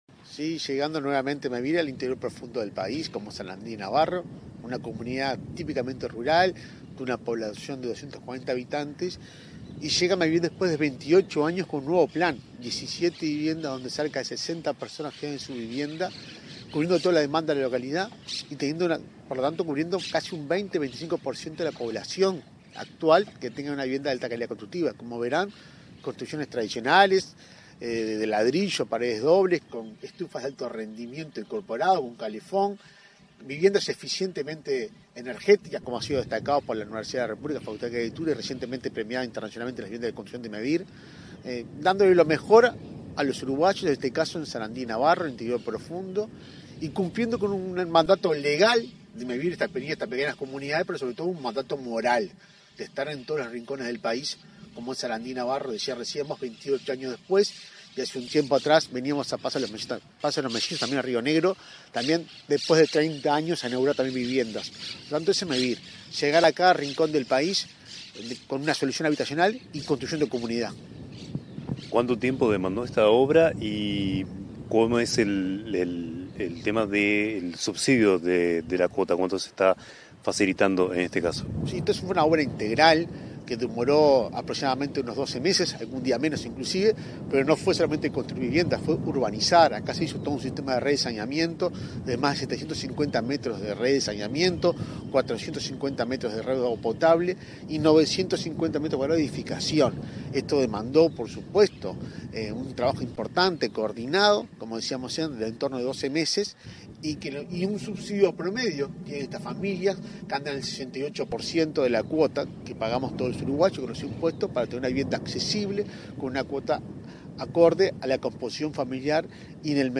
Entrevista al presidente de Mevir, Juan Pablo Delgado
El presidente de Mevir, Juan Pablo Delgado, dialogó con Comunicación Presidencial, antes de inaugurar viviendas rurales en la localidad de Sarandí